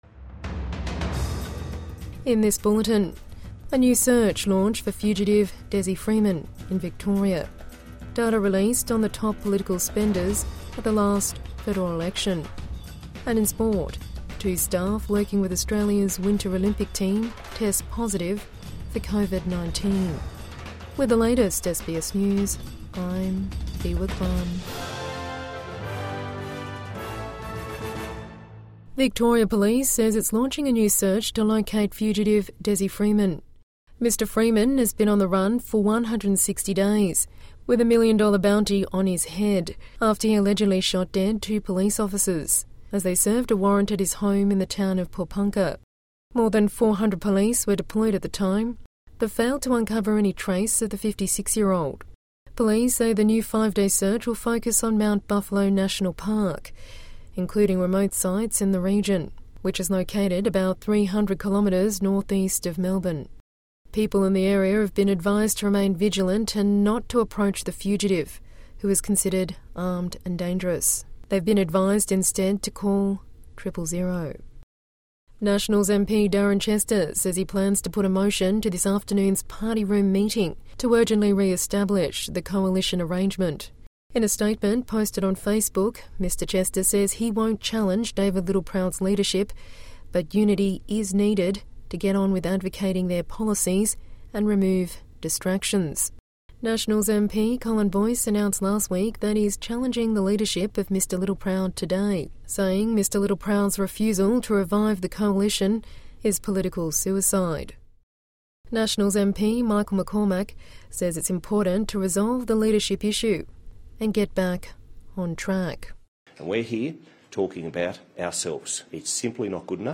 Midday News Bulletin 2 February 2026